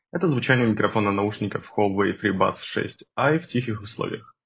Микрофон Huawei Freebuds 6i отличного качества на 9 / 10.
В тихих условиях: